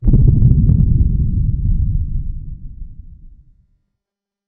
Distant Explosion
A muffled explosion heard from far away with delayed shockwave rumble and debris
distant-explosion.mp3